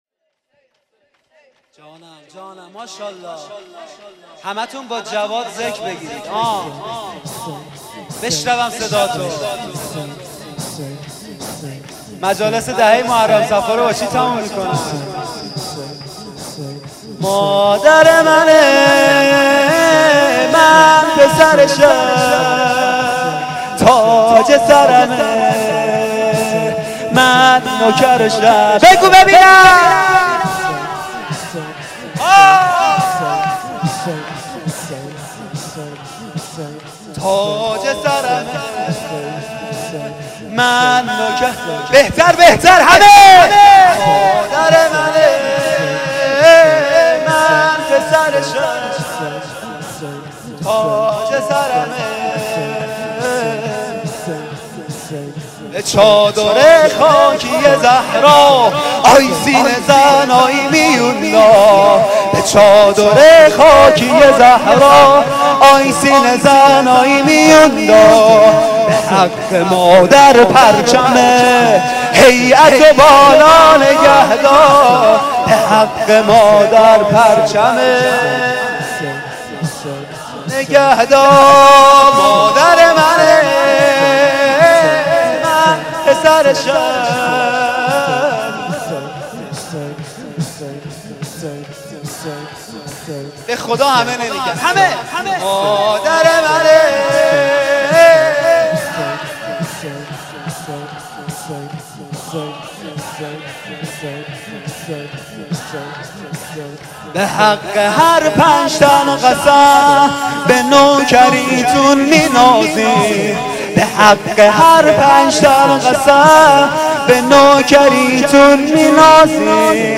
شور و مناجات پایانی.mp3
شور-و-مناجات-پایانی.mp3